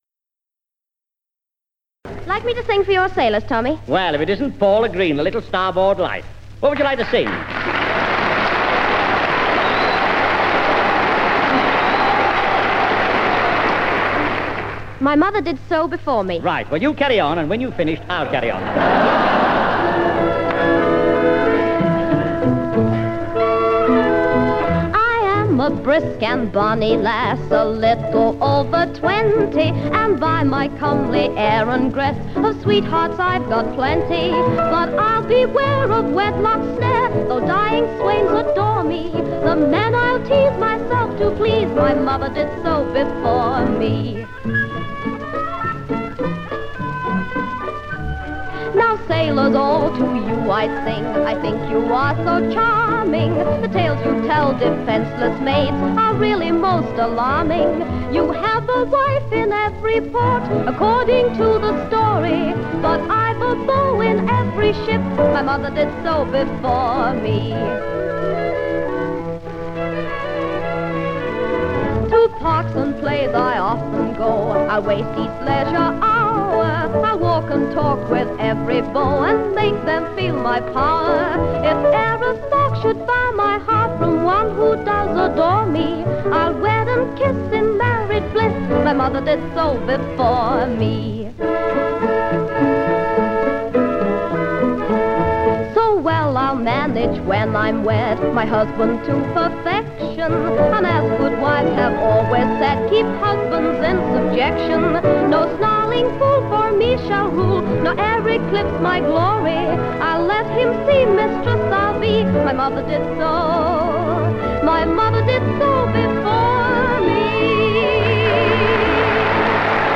And the BBC Variety Orchestra accompanied popular singer Paula Green in the I.T.M.A. 'Navy Number'.